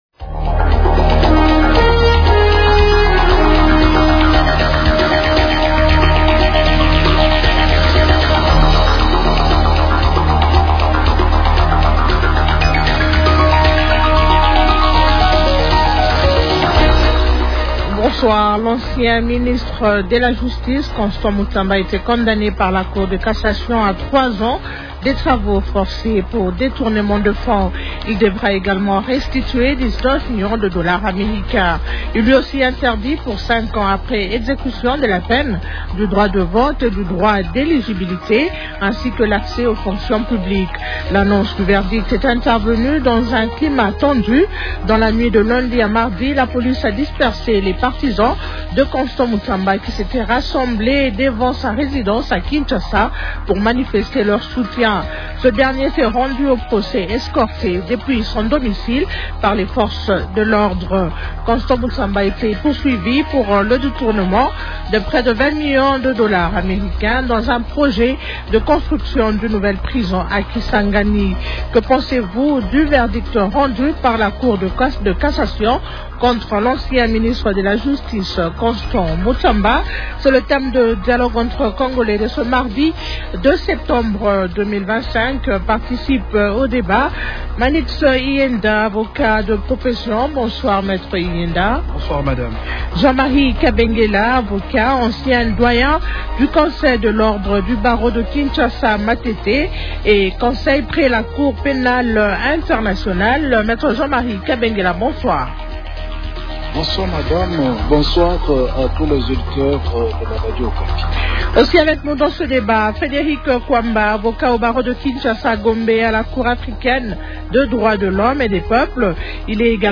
L'actualité politique de ce soir